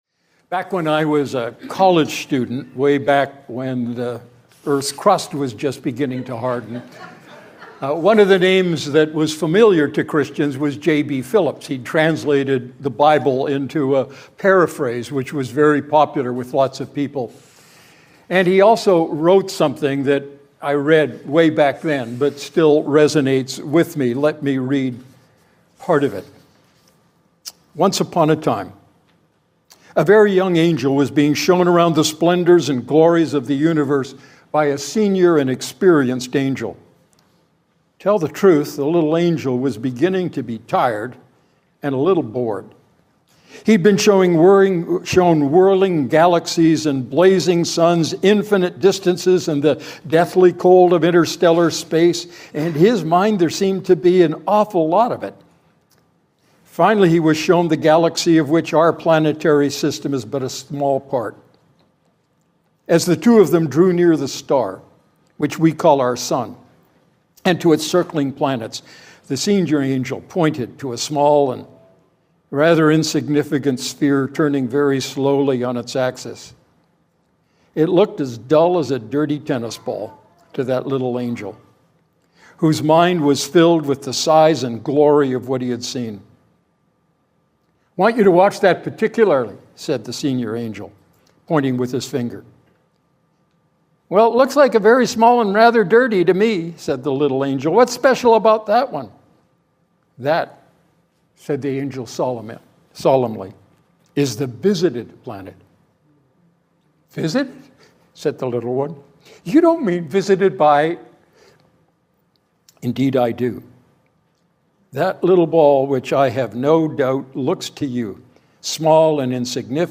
Sermon Archive, Redeemer Fellowship